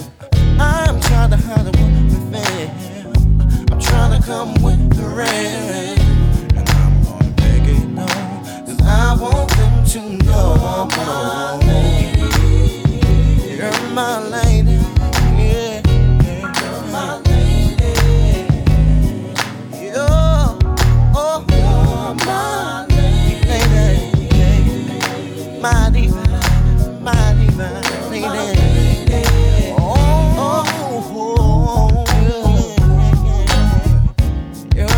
Плавные вокальные партии и чувственный бит
Глубокий соул-вокал и плотные хоровые партии
Жанр: R&B / Соул